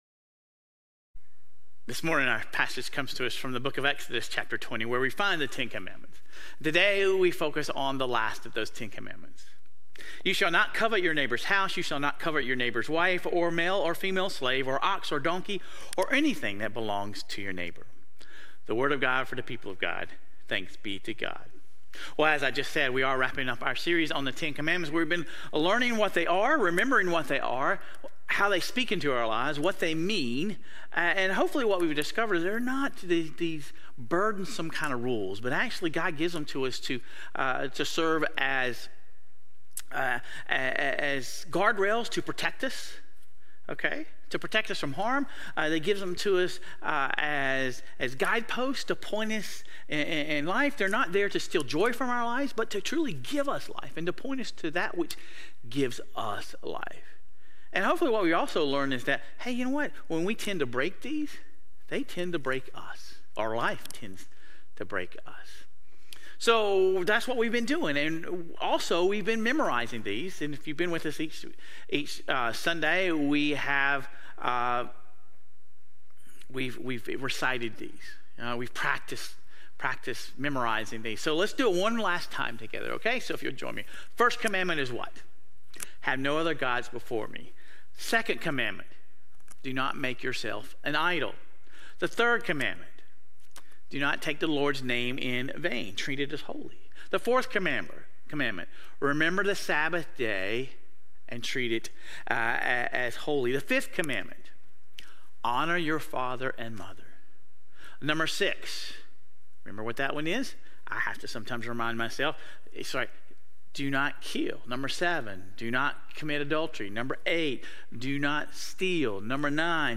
Sermon Reflections: When you think about your own "if only" statements, what areas of your life do you find yourself most frequently coveting things that others have?